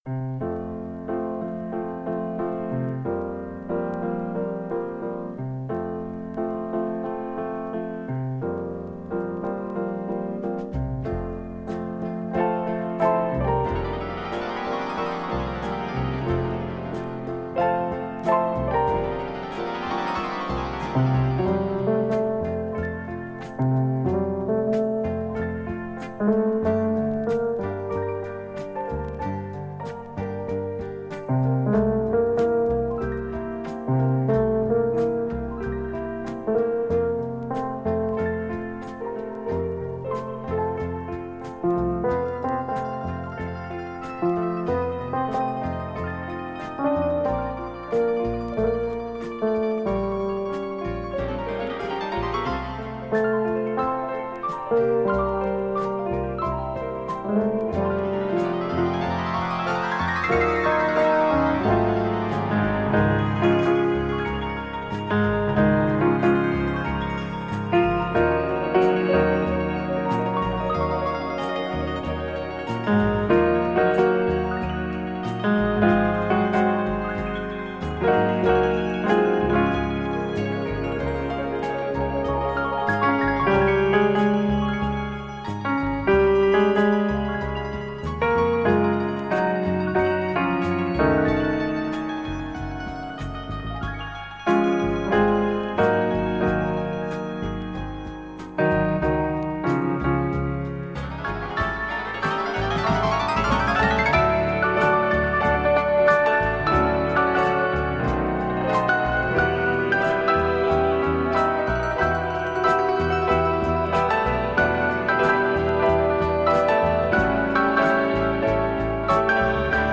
Genre:Electronic,Jazz,pop,Easy Listening